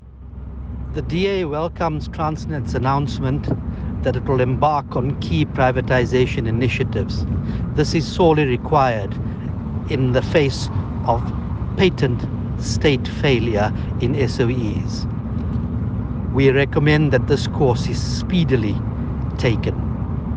soundbite by Ghaleb Cachalia MP.